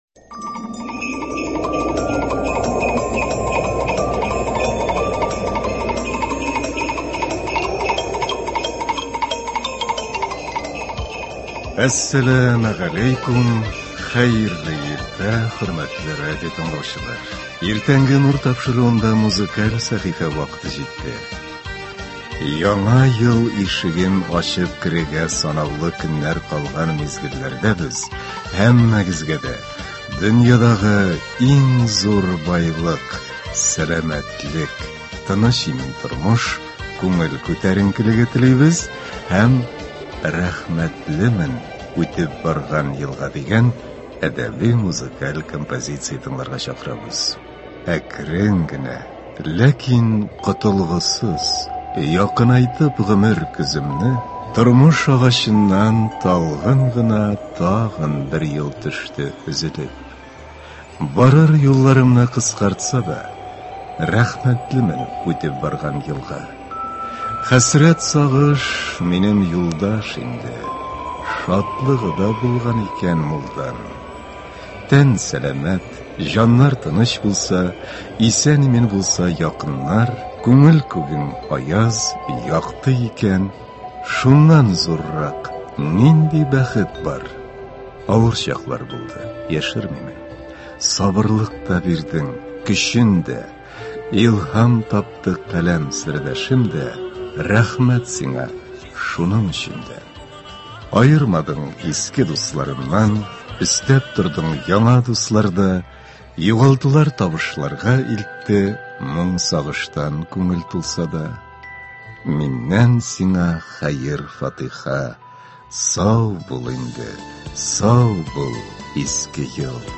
Иртәнге концерт.